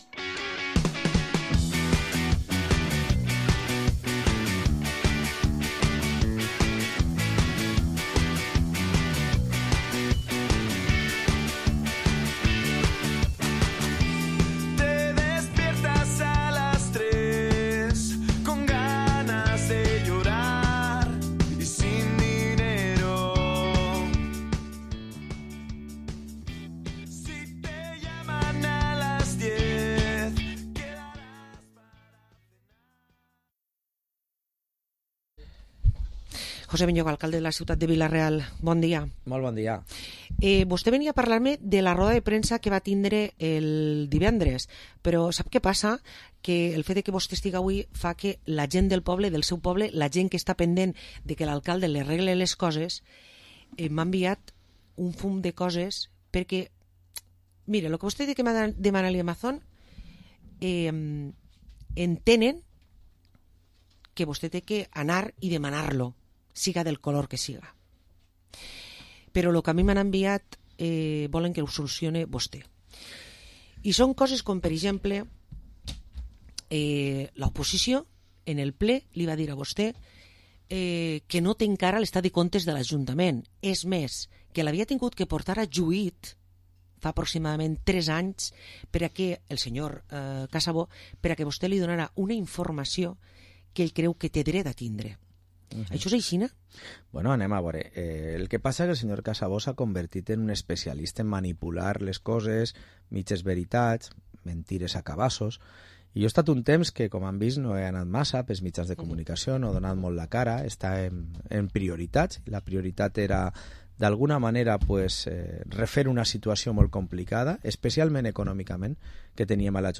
Parlem amb l´alcalde de Vila-real, José Benlloch
03-02-25-jose-benlloch-alcalde-vila-real.mp3